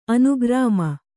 ♪ anugrāma